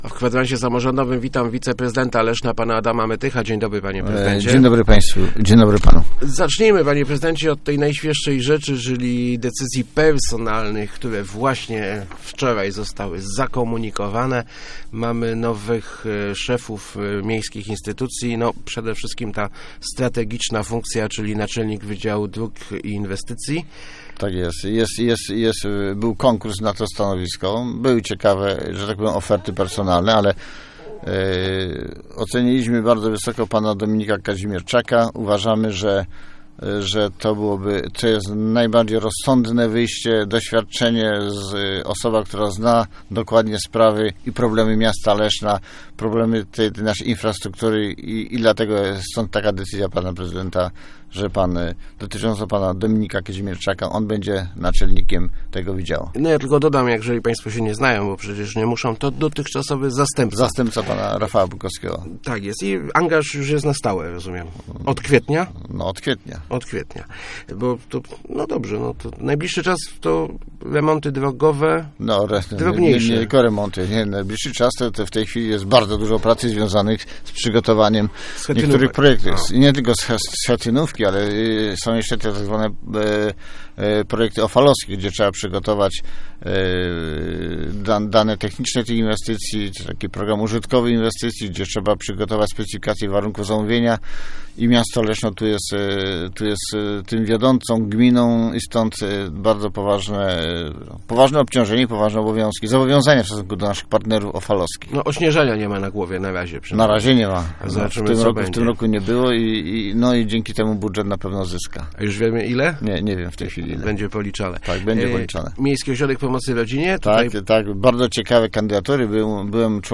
Gościem Kwadransa był wiceprezydent Adam Mytych ...